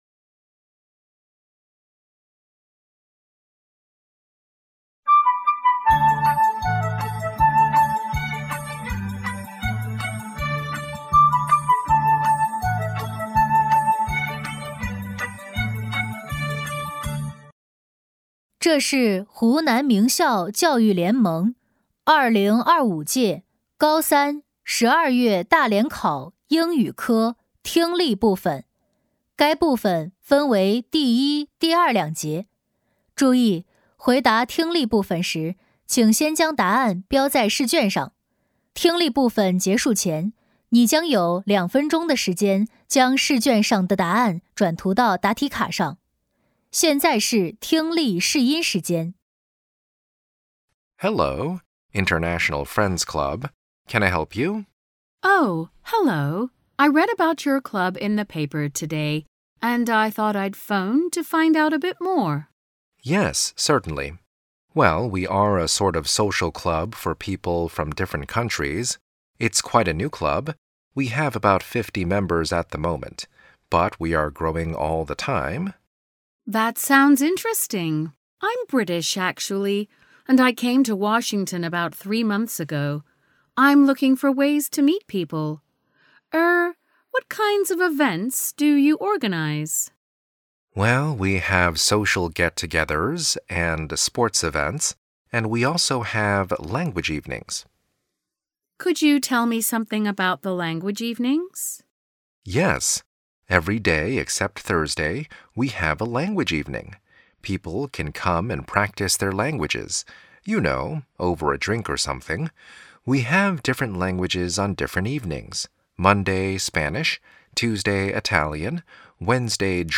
湖南省名校教育联盟2025届高三上学期12月大联考英语听力.mp3